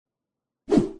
Free UI/UX sound effect: Laser Click.
Laser Click
# laser # click # sci-fi # ui About this sound Laser Click is a free ui/ux sound effect available for download in MP3 format.
510_laser_click.mp3